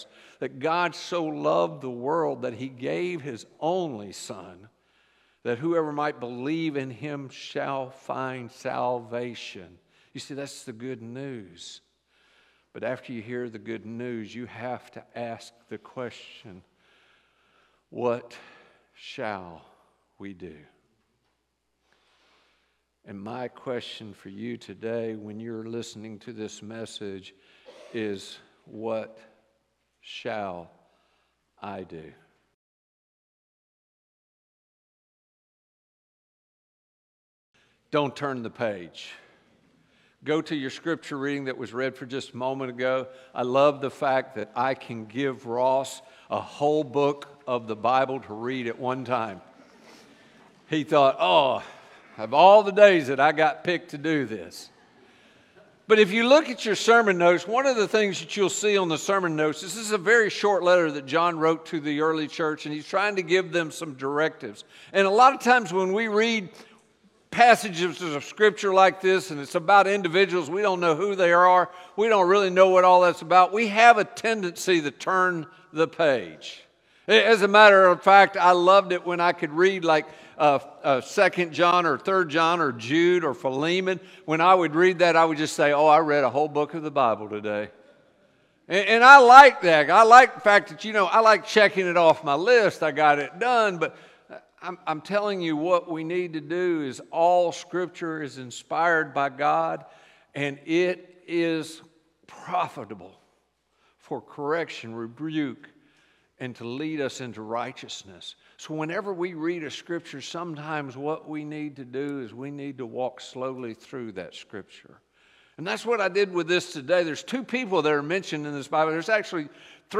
Recording from North Tampa Church of Christ in Lutz, Florida.
Sermon Turn the page Week 2